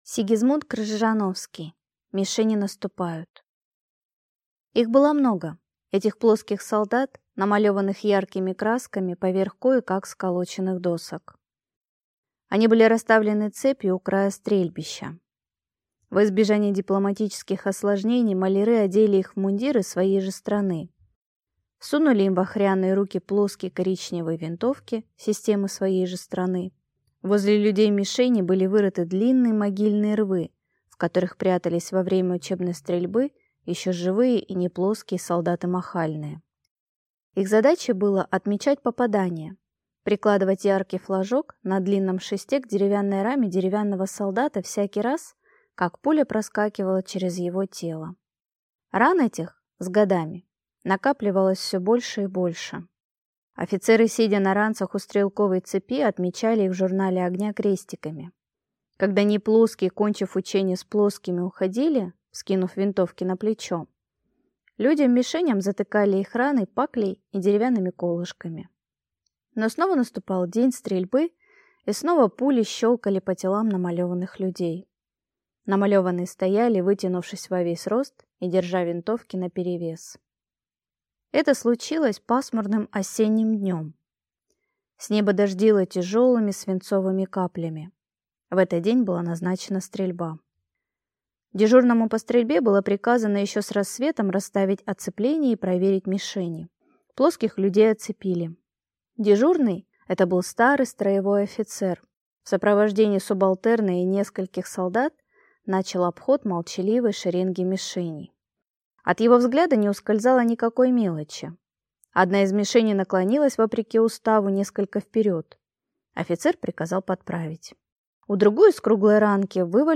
Аудиокнига Мишени наступают | Библиотека аудиокниг